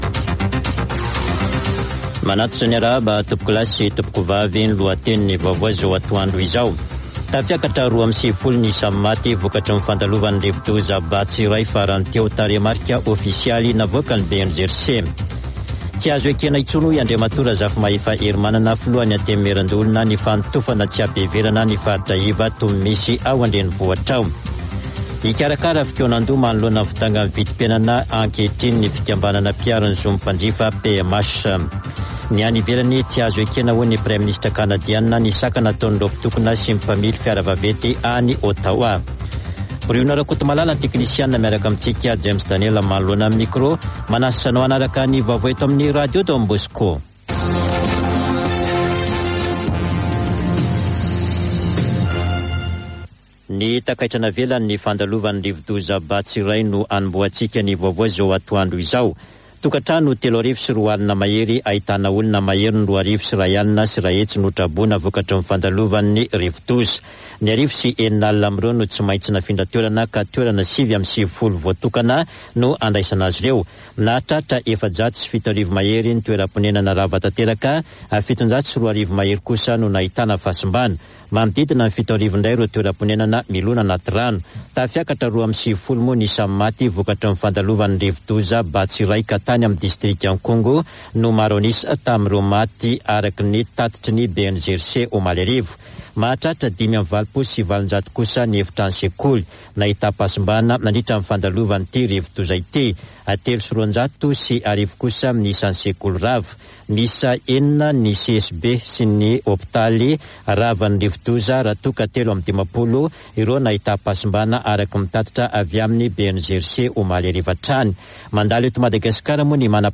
[Vaovao antoandro] Alakamisy 10 febroary 2022